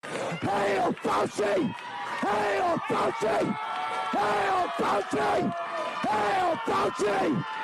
Sci_chant.mp3